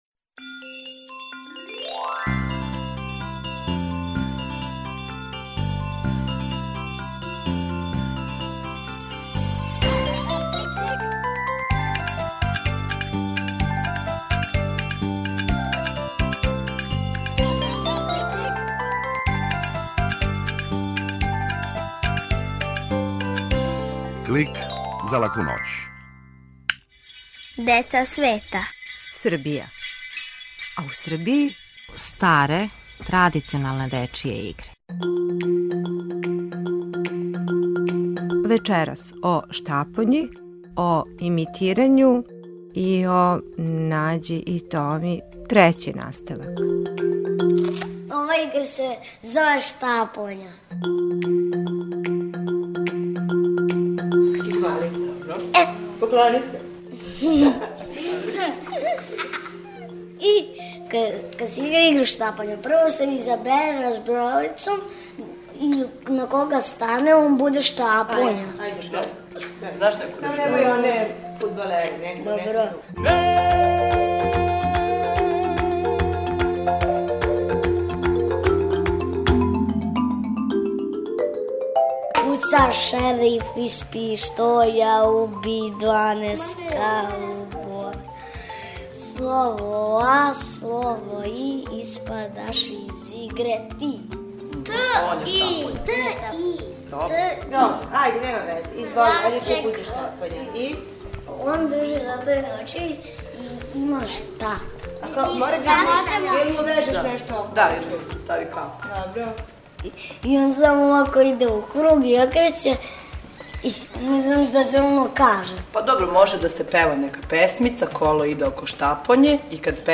Клик је кратка емисија за децу, забавног и едукативног садржаја. Сваке седмице наши најмлађи могу чути причу о деци света, причу из шуме, музичку упознавалицу, митолошки лексикон и азбуку звука. Уколико желите да Клик снимите на CD или рачунар, једном недељно,на овој локацији можете пронаћи компилацију емисија из претходне недеље, које су одвојене кратким паузама.